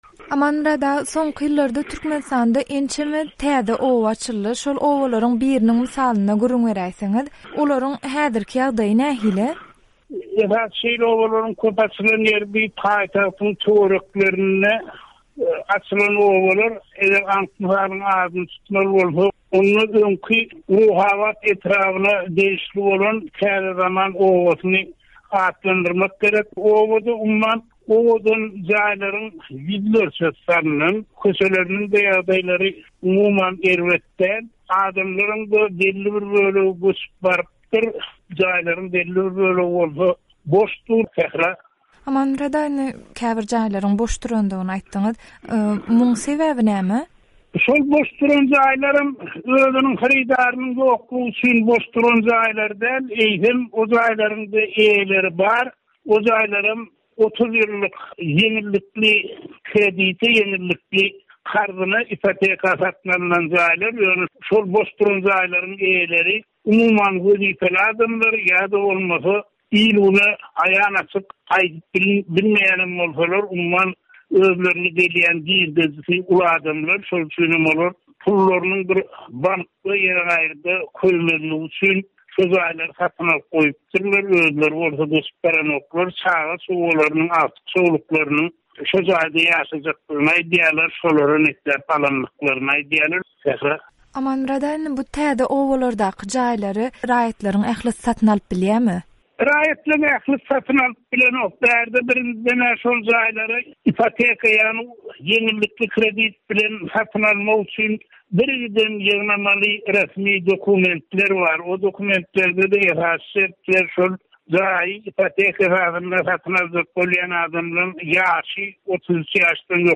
by Azatlyk Radiosy